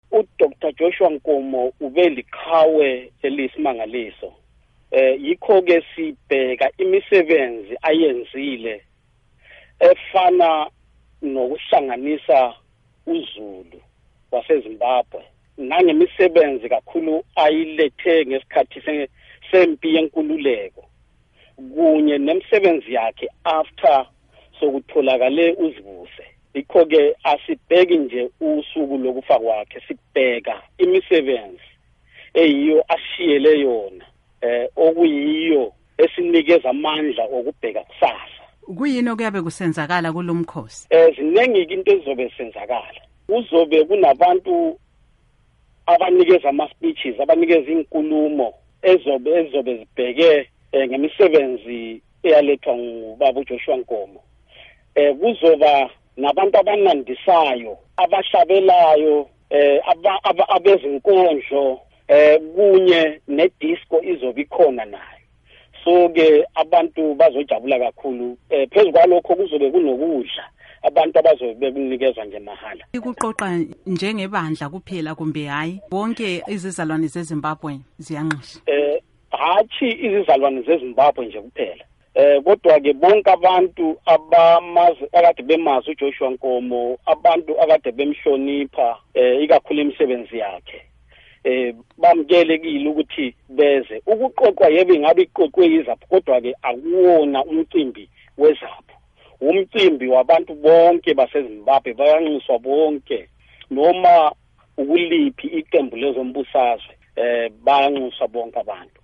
Ingxoxo